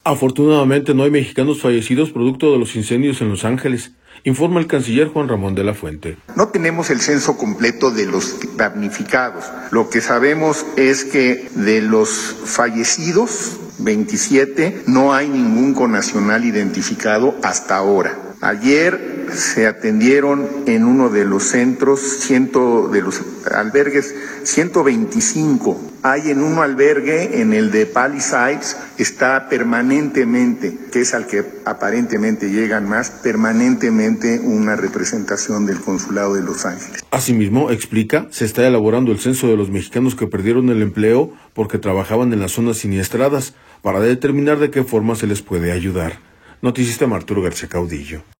Afortunadamente no hay mexicanos fallecidos producto de los incendios en Los Ángeles, informa el canciller Juan Ramón De la Fuente.